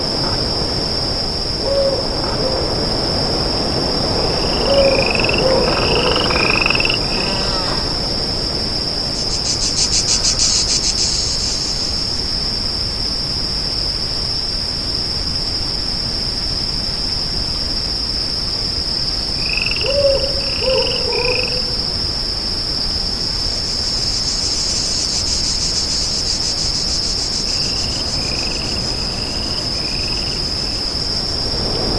ambient_night.ogg